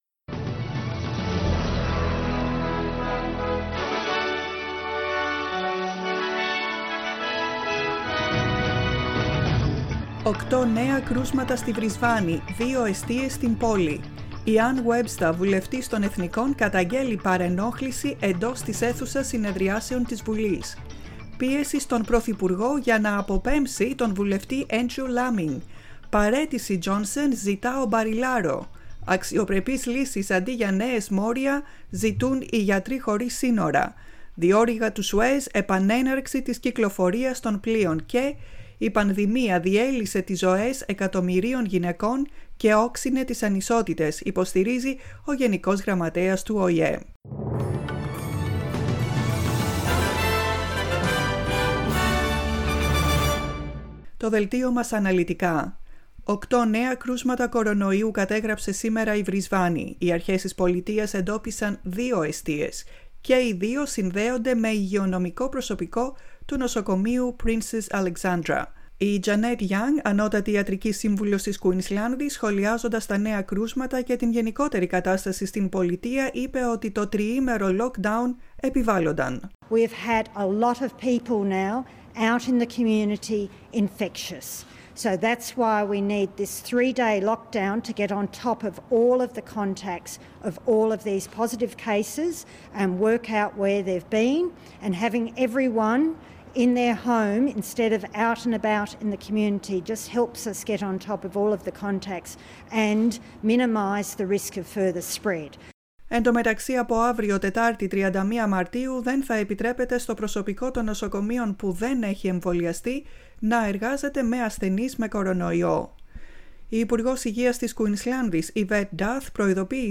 News in Greek, 30.03.21